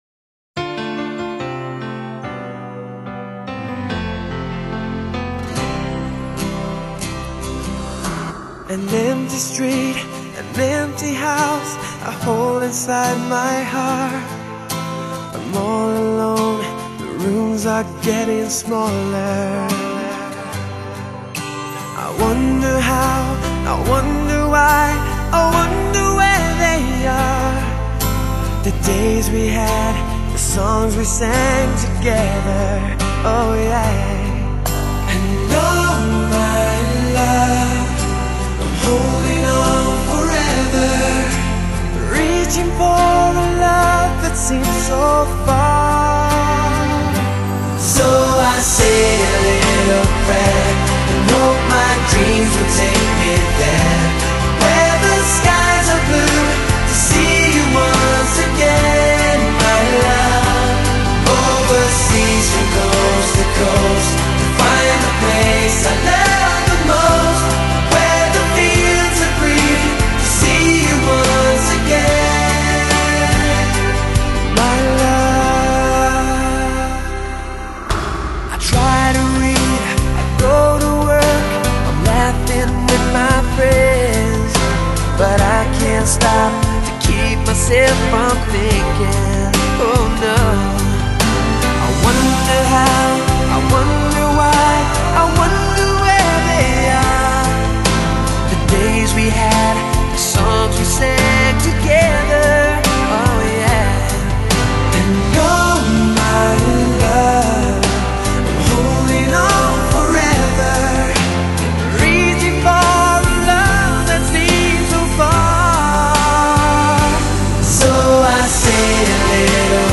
Genre: Pop, Soul, R&B, Ballad